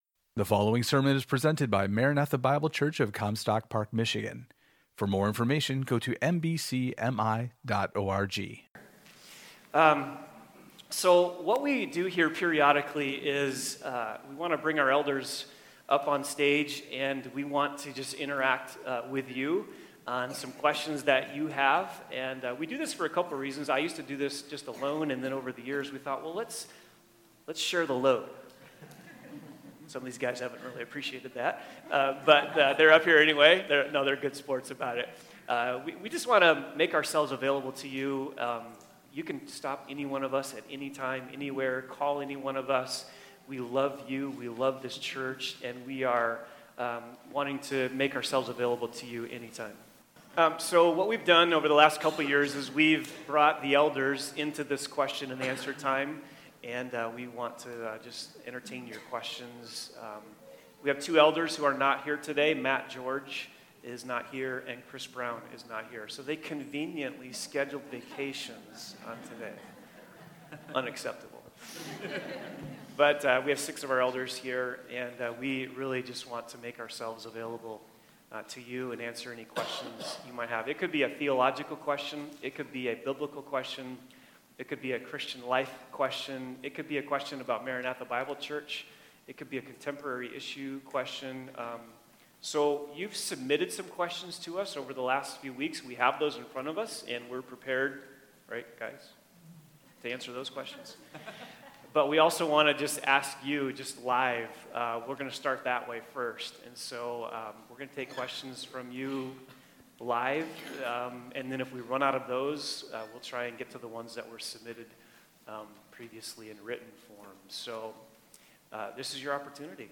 Elders’ Q&A
Questions the elders dealt with today: